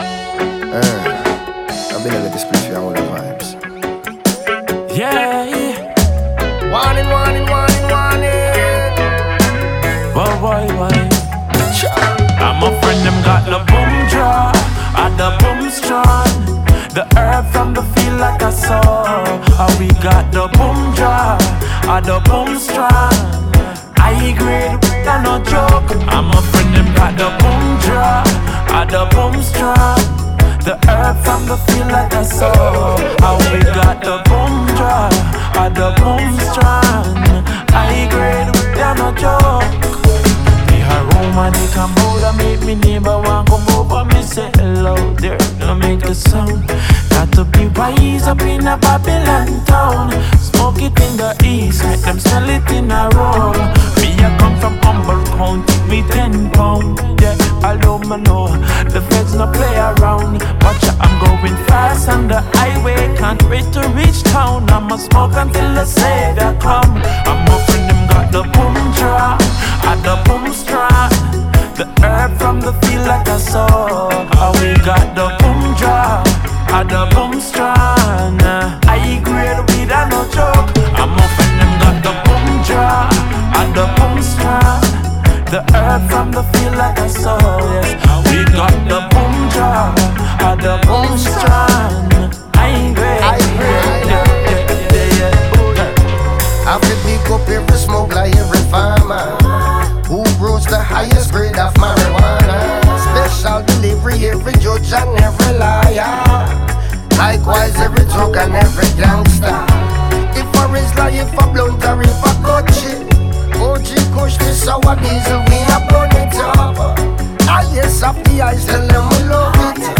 Genre: Reggae
soulfully-soothing, sing jay-styled song